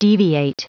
Prononciation du mot deviate en anglais (fichier audio)
Prononciation du mot : deviate